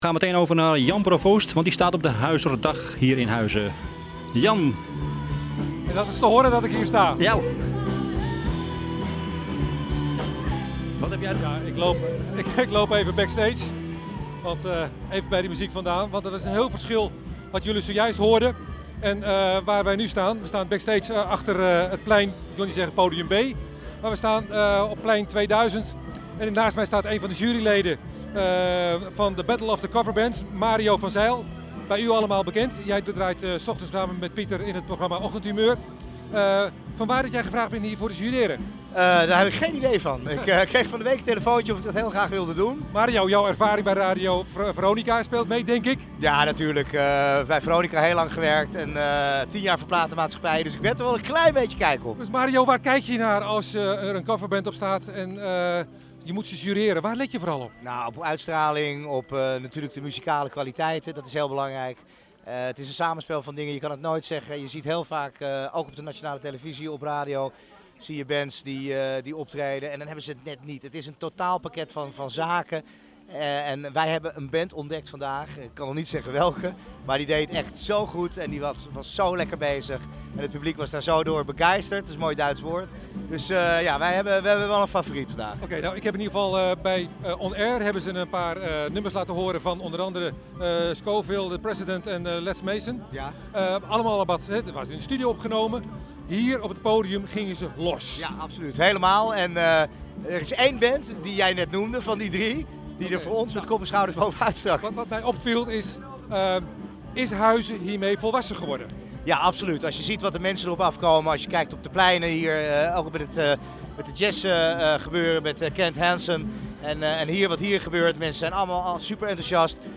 Verslag van de Huizerdag op Plein 2000.